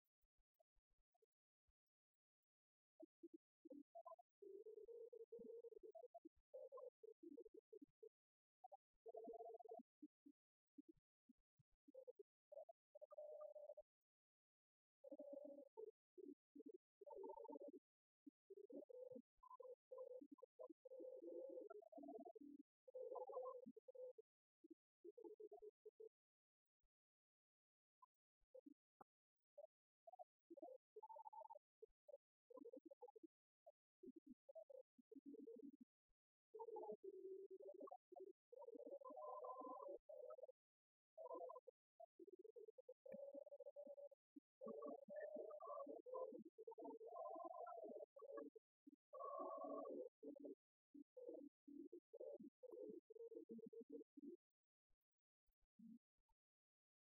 Concerts de chorales
Pièce musicale inédite